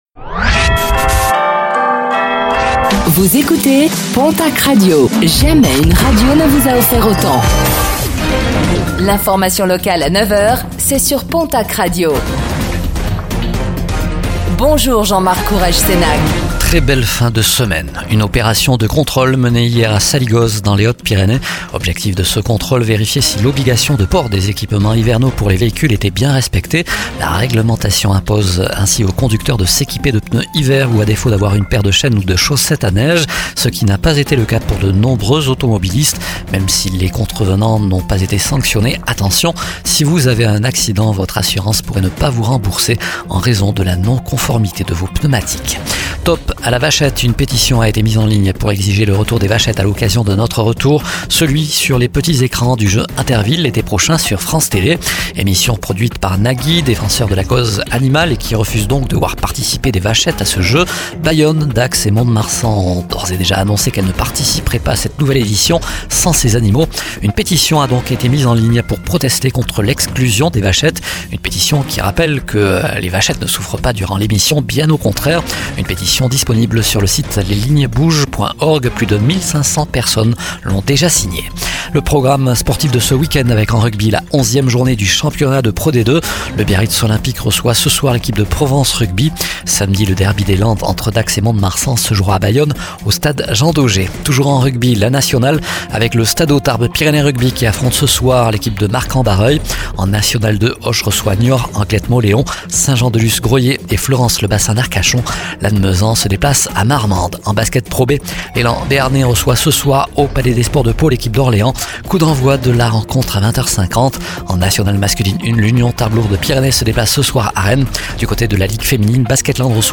09:05 Écouter le podcast Télécharger le podcast Réécoutez le flash d'information locale de ce vendredi 15 novembre 2024